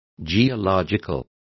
Complete with pronunciation of the translation of geological.